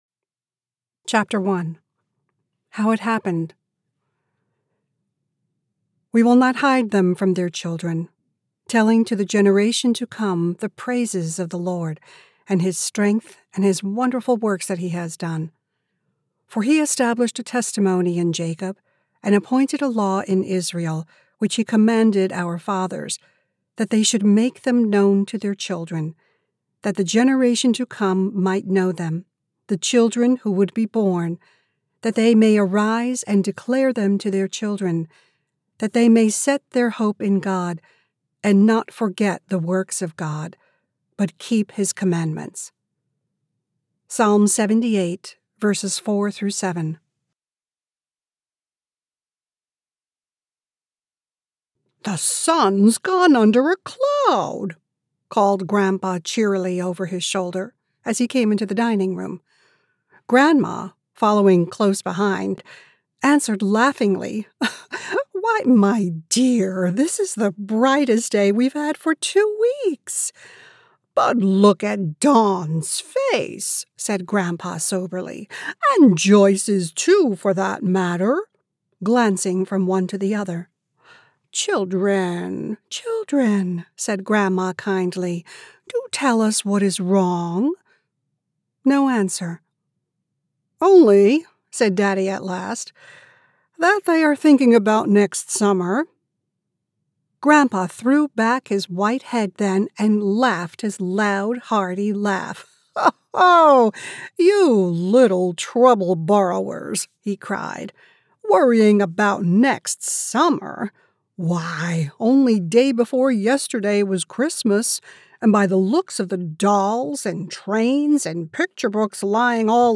A Hive of Busy Bees - Audiobook